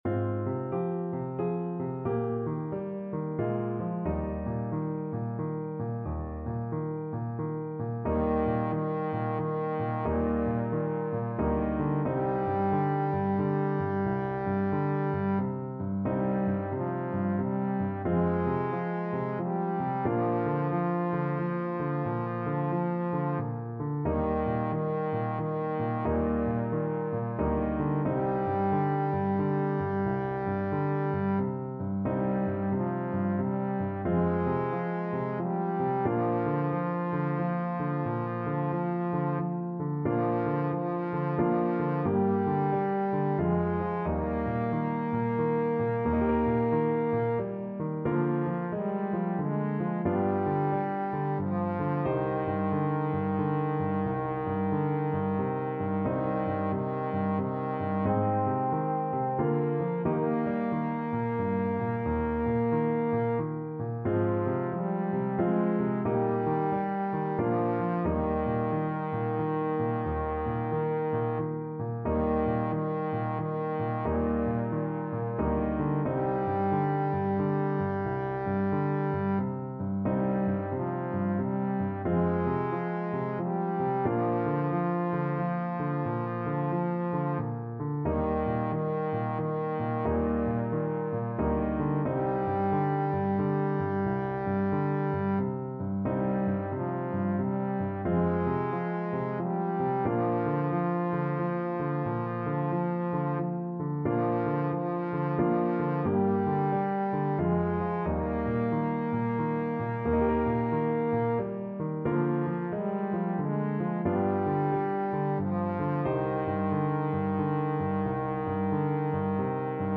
Trombone
Eb major (Sounding Pitch) (View more Eb major Music for Trombone )
=90 Andante, gentle swing
3/4 (View more 3/4 Music)
Traditional (View more Traditional Trombone Music)